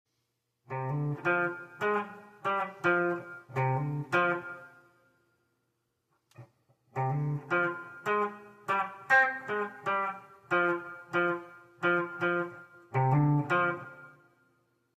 A little phrase using the F major blues scale on the guitar!